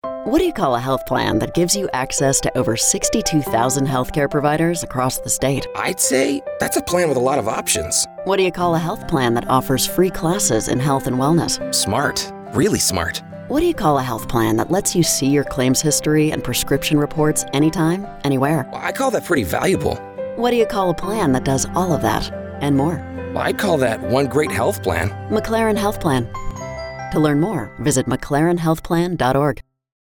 Radio Spot
FINALMHP-Brand-Radio_R2.mp3